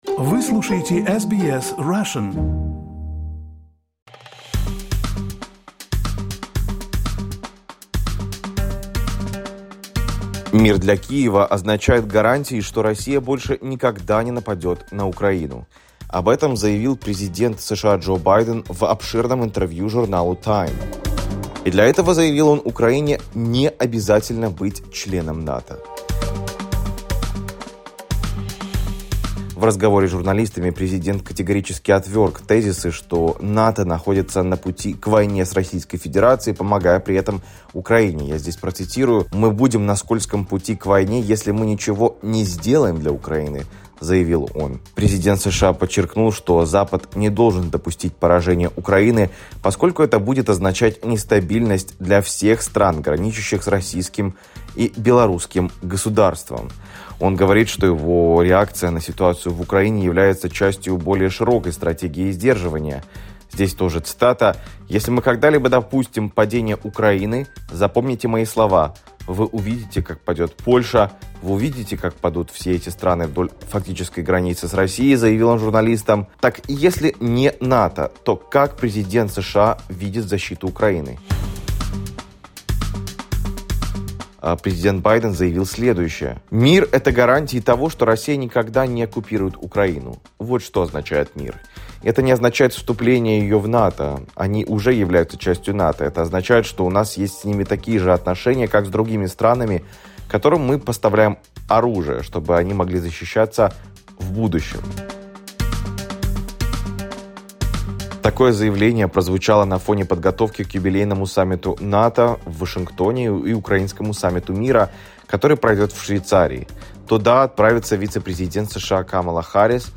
We spoke with former US Ambassador to Ukraine John Herbst about what Kyiv can expect from the July NATO summit.